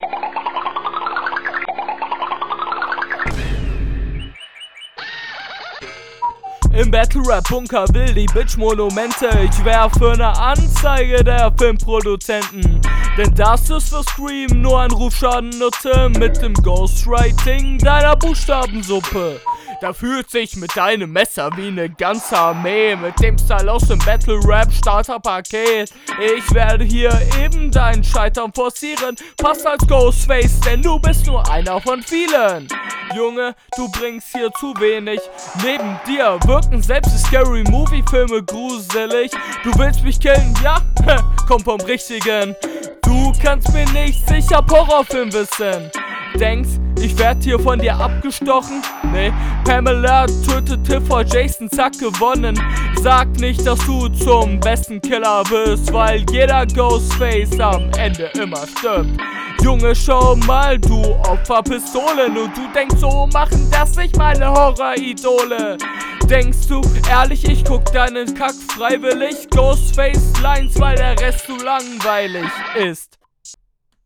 Die Pausen zwischen den Worten sind teilweise viel zu groß und zu viel.
ich finde die Betonungen gelungen...
Betonung komisch, klingst betrunken Bruder. Beat natürlich sehr eigenartig.